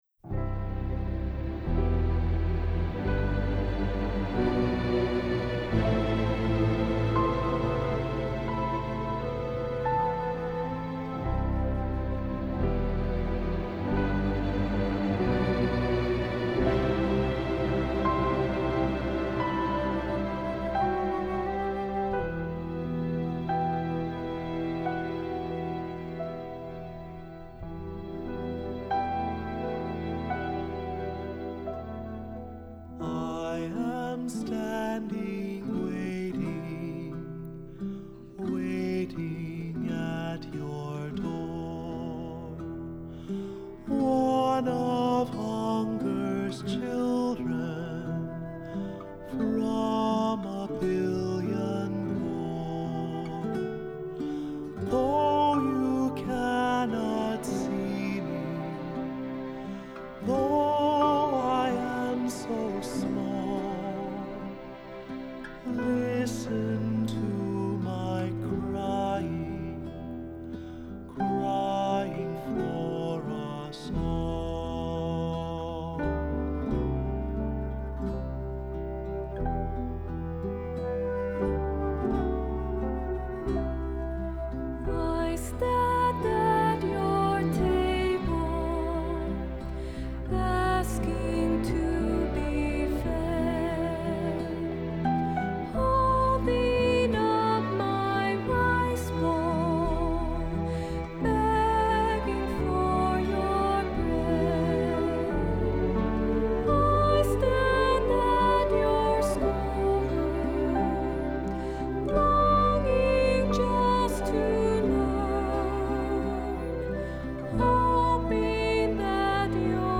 Accompaniment:      Keyboard
Music Category:      Christian
hymn-anthem
For cantor or soloist.